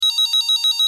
Vstup 1 - gong BIM-BAM
Melodie v MP3 melodie Bim-bam
Hlasitost vyzvánění 78  dB